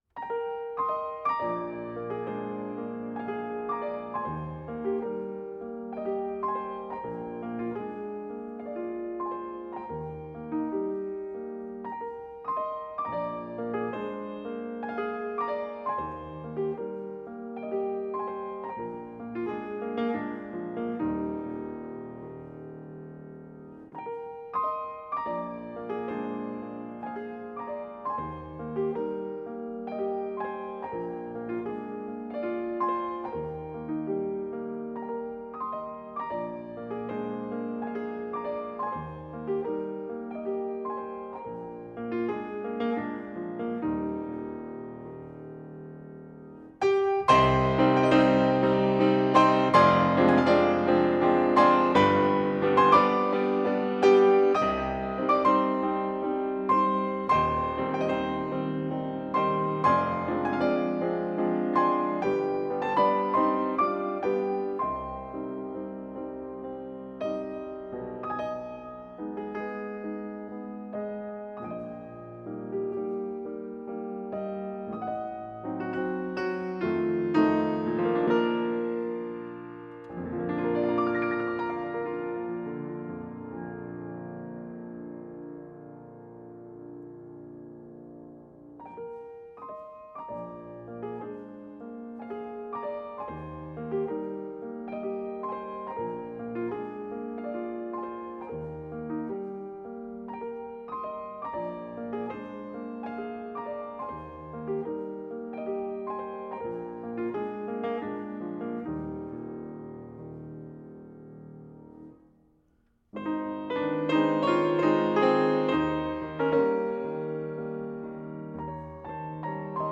Complete Performance *#476818
piano
Notes Recorded June 2017 in St. Paul's Hall, Huddersfield University